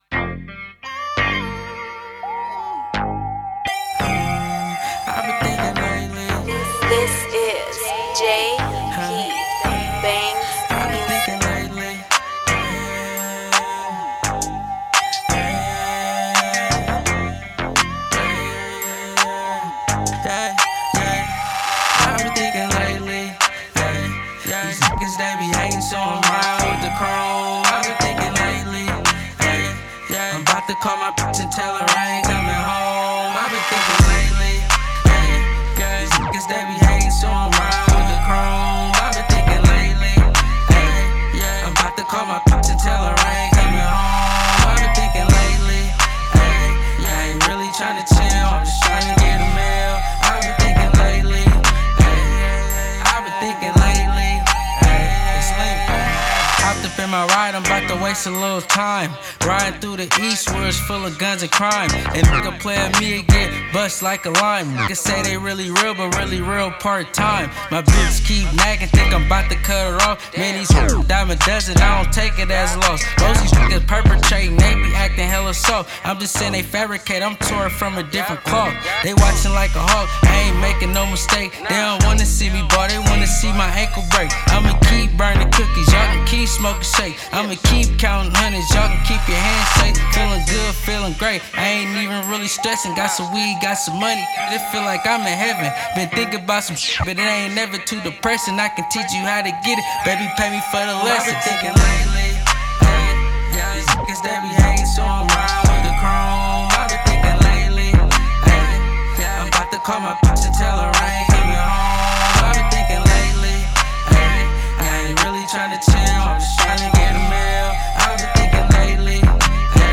Hiphop
Some West Coast Funky Hip Hop Vibes